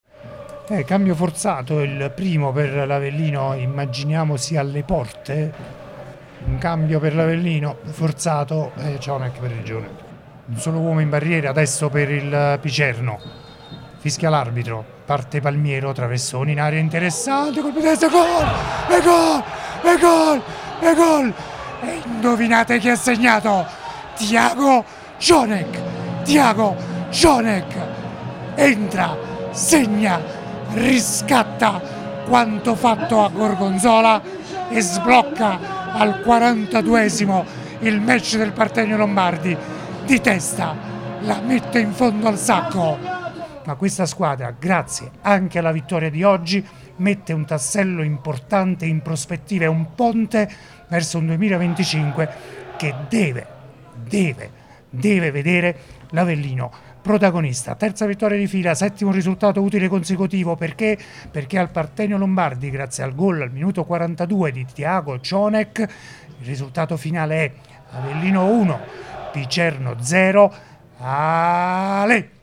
PODCAST | CIONEK ENTRA E SEGNA IL GOL VITTORIA: RIASCOLTA L’ESULTANZA